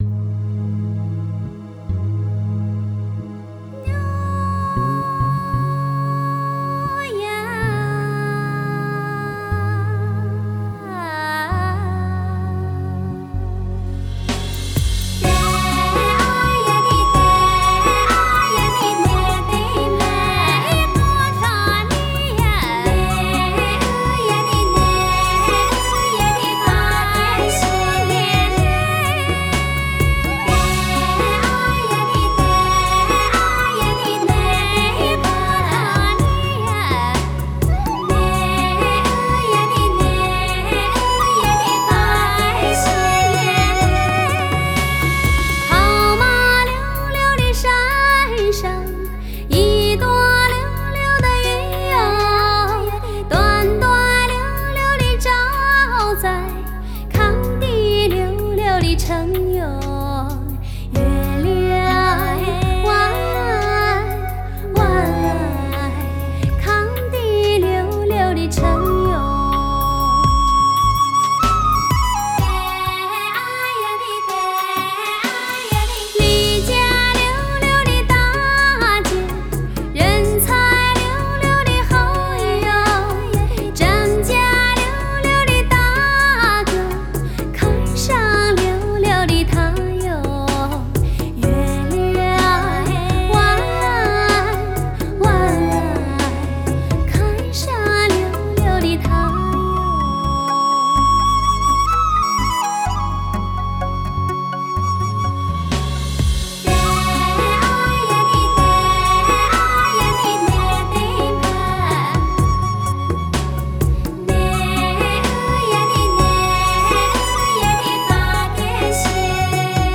Жанр: Modern Traditional / Cinese pop / Miao folk